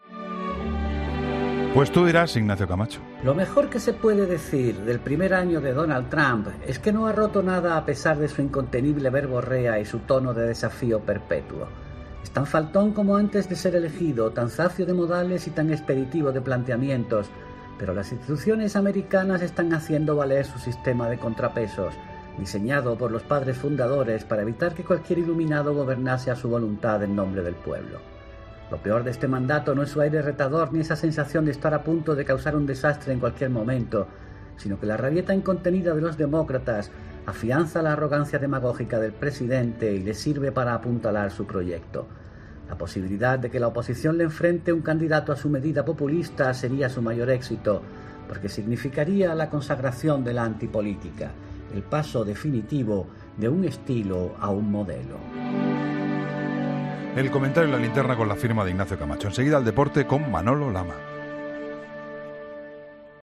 ESCUCHA EL COMENTARIO COMPLETO | Ignacio Camacho en ‘La Linterna’